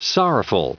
Prononciation du mot sorrowful en anglais (fichier audio)
Prononciation du mot : sorrowful